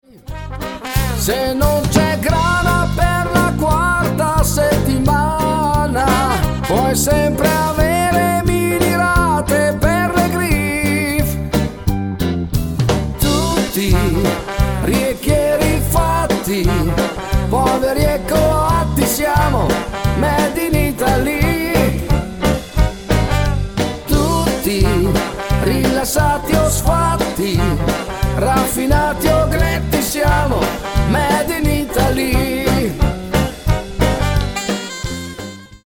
FOX - TROT  (3.00)